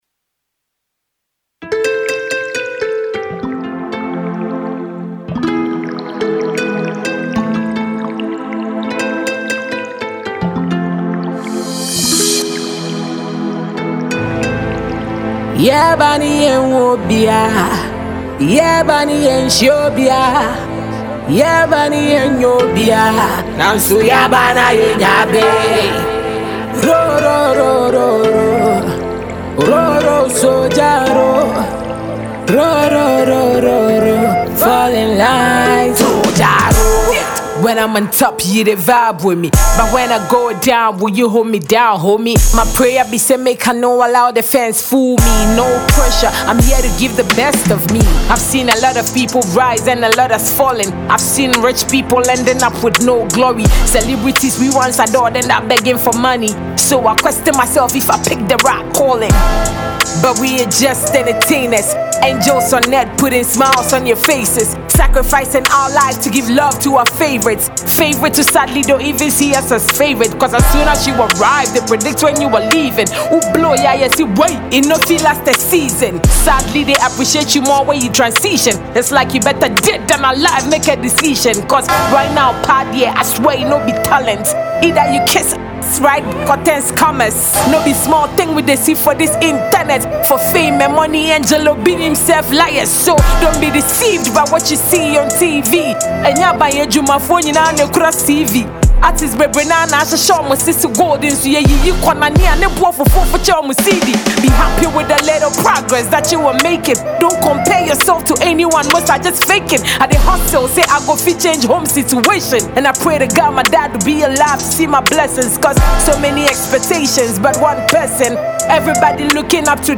a Ghanaian female rapper